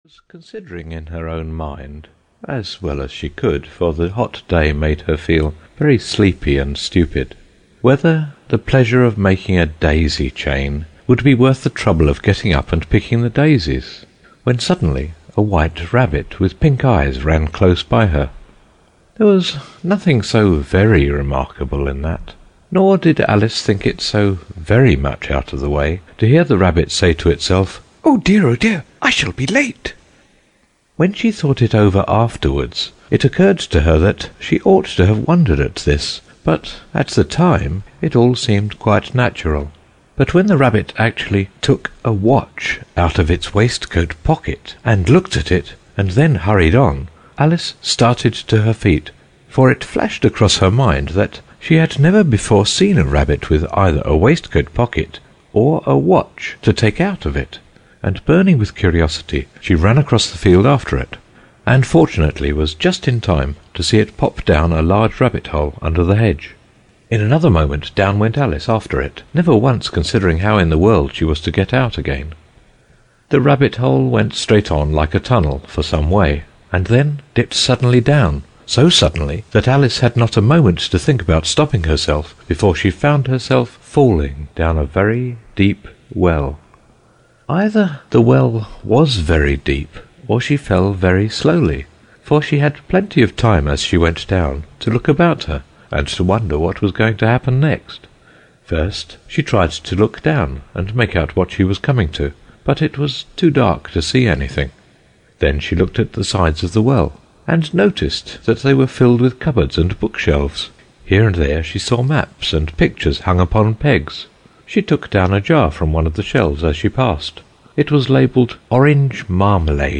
Alice s Adventures in Wonderland  (EN) audiokniha
Ukázka z knihy